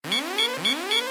missilewarn.ogg